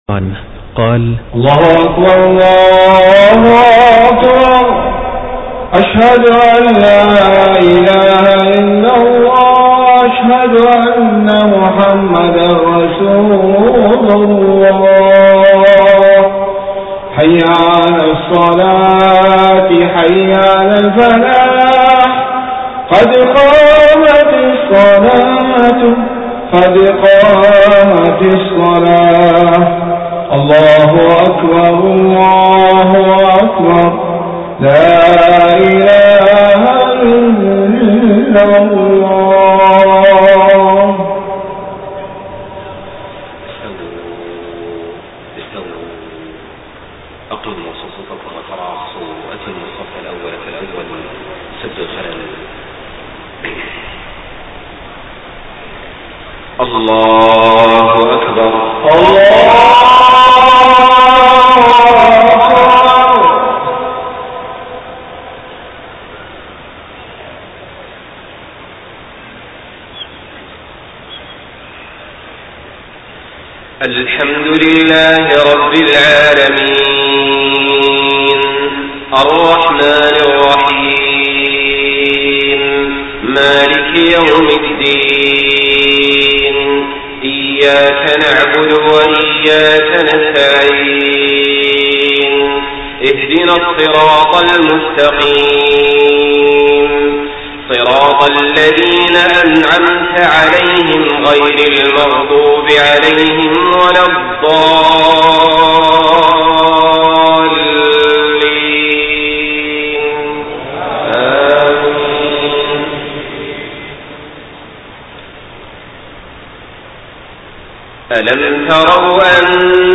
صلاة العشاء 8 ربيع الأول 1431هـ من سورة لقمان 20-30 > 1431 🕋 > الفروض - تلاوات الحرمين